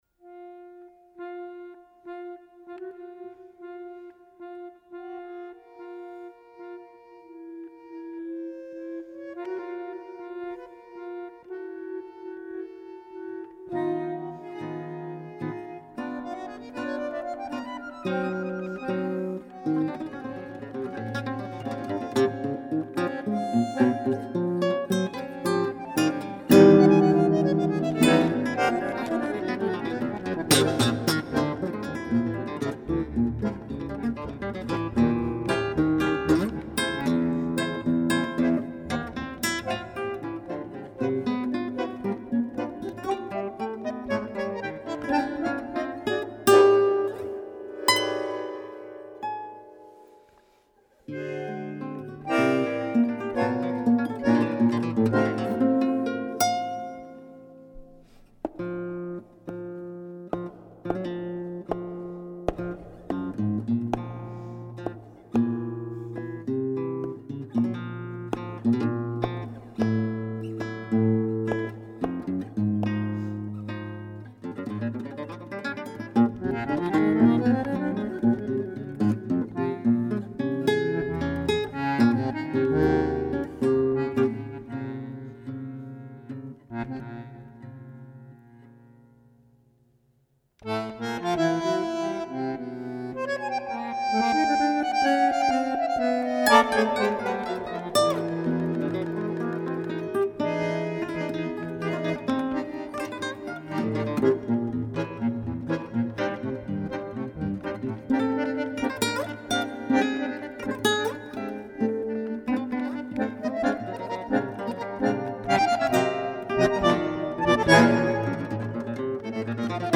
Dazu als Kontrast der Tango
gespielt von ihm selbst auf dem Bandoneon
Gitarre